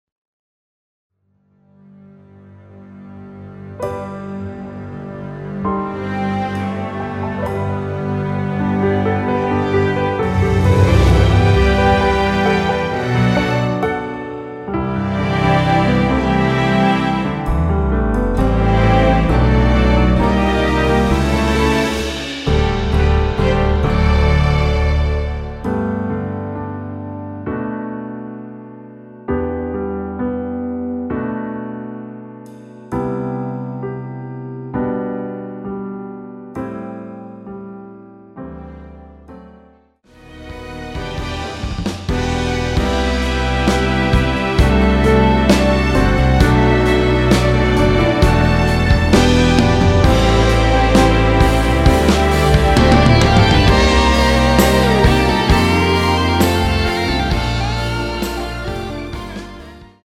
원키에서(-2)내린 MR입니다.
앞부분30초, 뒷부분30초씩 편집해서 올려 드리고 있습니다.
중간에 음이 끈어지고 다시 나오는 이유는